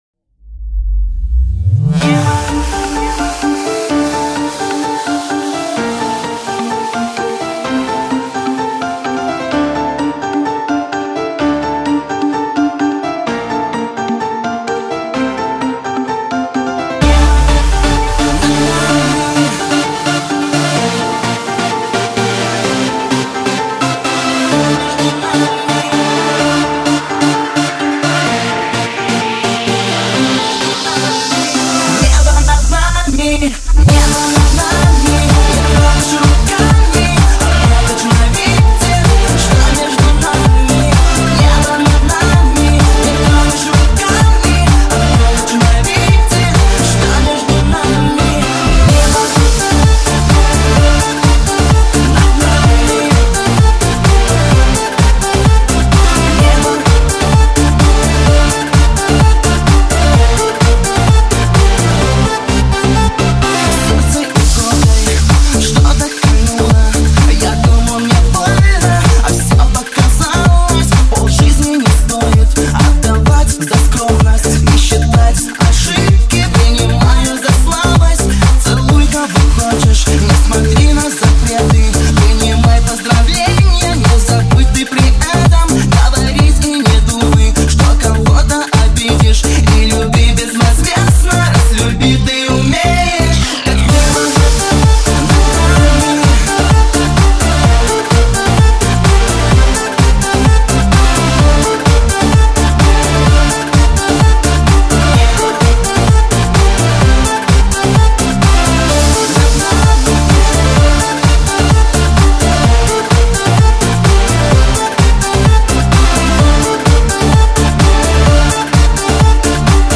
сочетание этно музыки с элементами танцевального жанра
его стихи пронизаны глубоким смыслом вокал легкий нежный